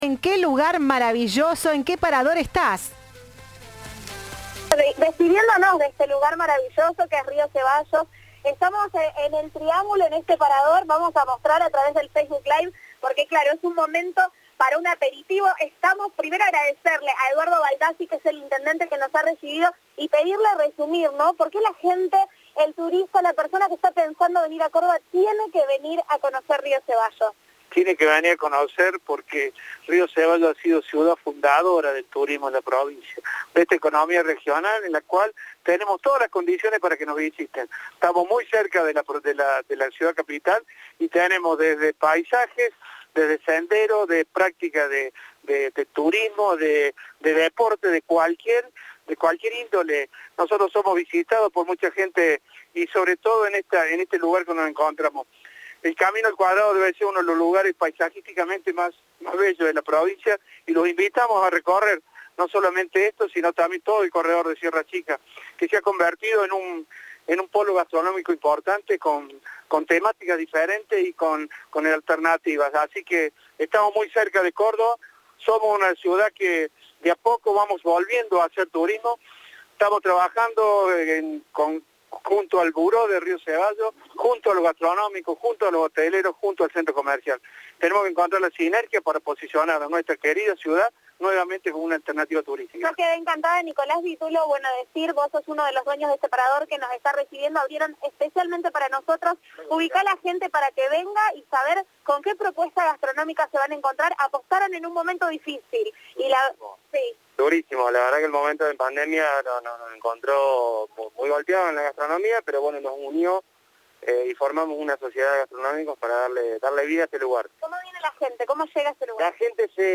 "Es a 40 minutos de Córdoba capital", dijo a Cadena 3 el intendente.